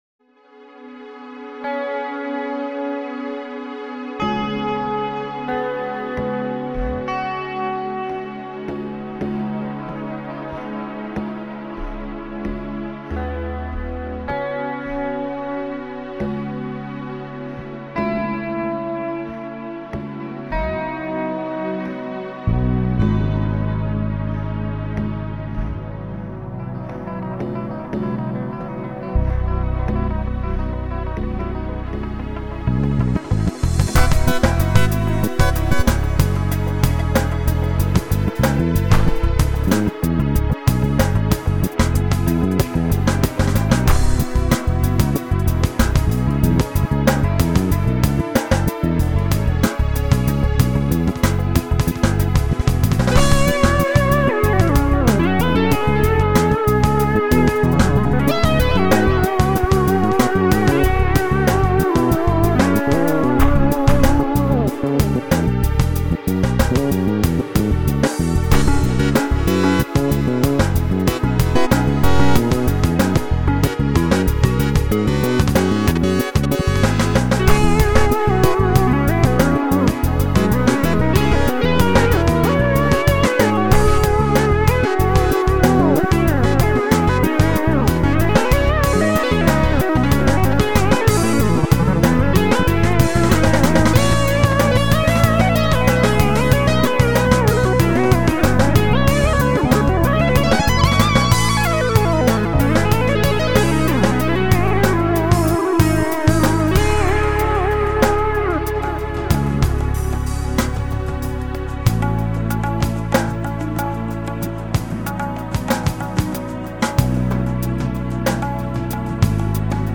Muzyka, kt�r� mo�na us�ysze� w naszych produkcjach prezentuje po��czenie brzmie� naturalnych (nagrywanych "na �ywo") i elektronicznych (syntetyzowanych przy u�yciu komputer�w).